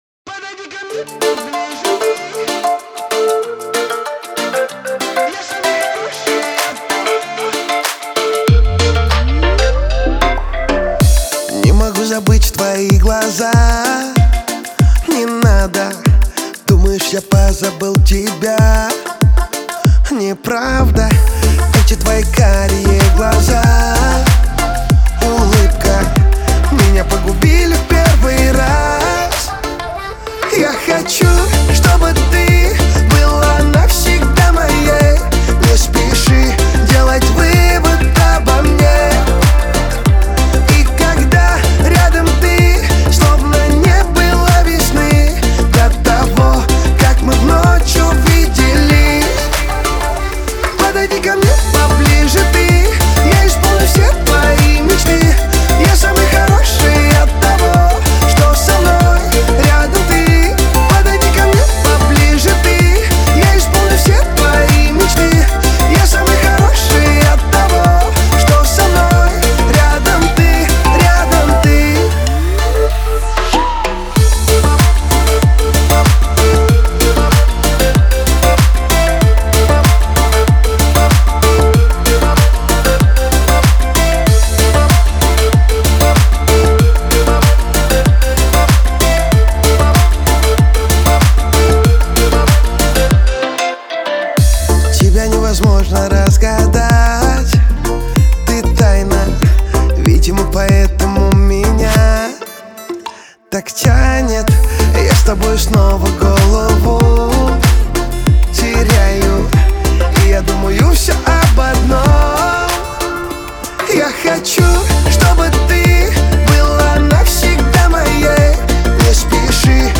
Лирика , эстрада
pop